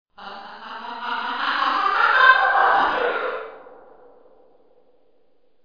Sound Effects
Weird Laugh